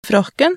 Preaspirasjon (f.eks. ) er aspirasjonsfasen som opptrer før en plosiv: "frakken" uttalt